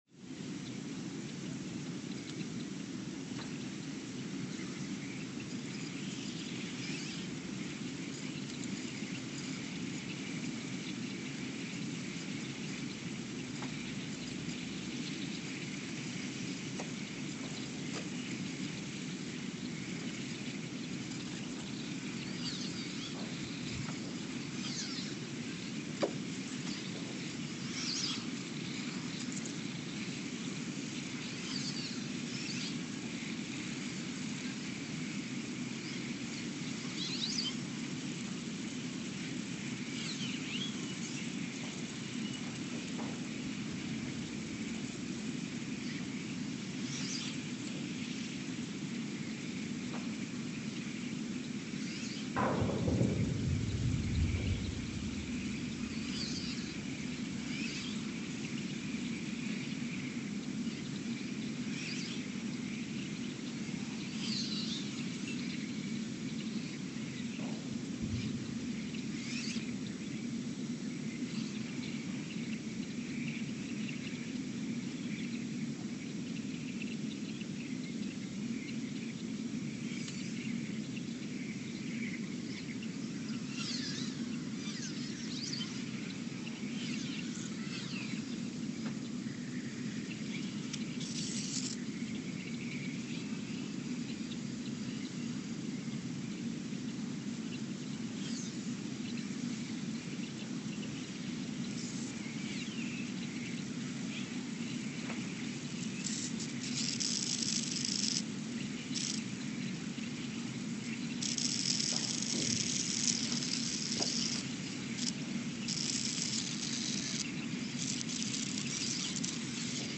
Ulaanbaatar, Mongolia (seismic) archived on July 1, 2023
Sensor : STS-1V/VBB
Speedup : ×900 (transposed up about 10 octaves)
Loop duration (audio) : 03:12 (stereo)